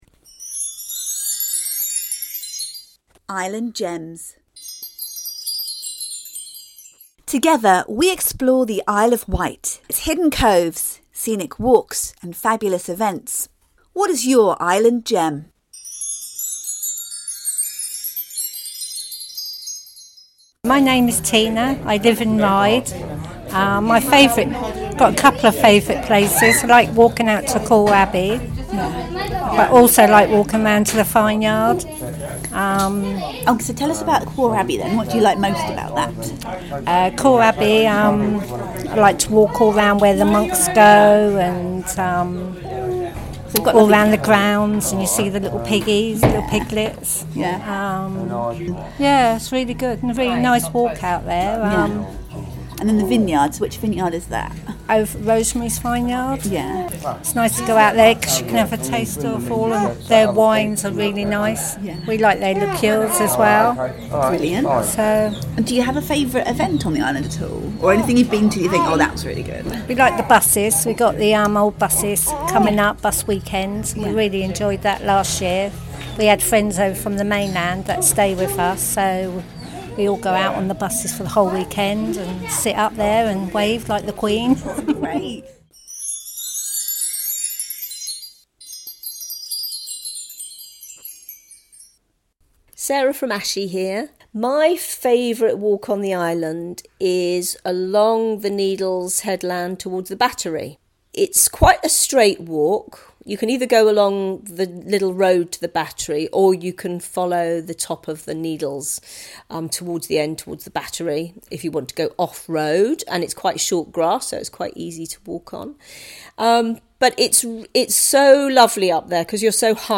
Islanders tell us of their hidden gems and secret treasures on the island - from beaches and walks to events and landmarks..